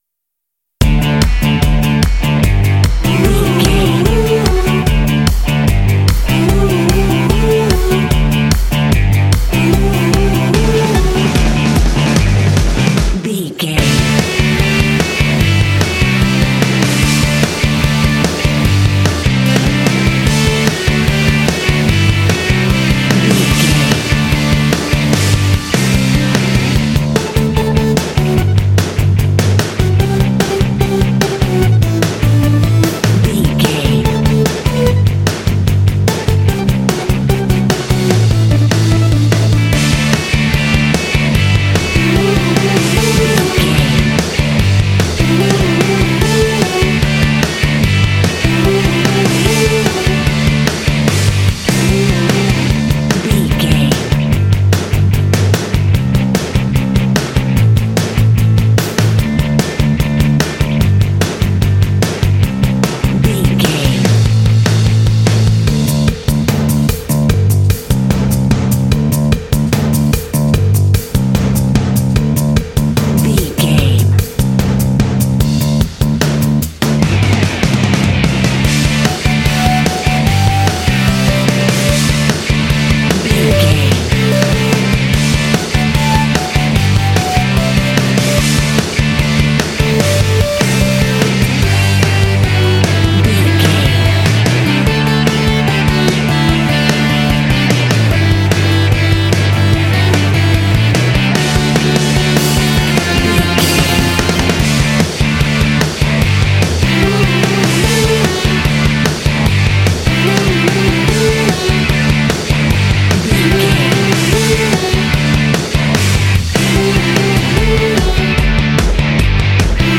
This cute indie track is ideal for action and sports games.
Fast paced
Ionian/Major
Fast
energetic
cheerful/happy
electric guitar
bass guitar
drums
classic rock
alternative rock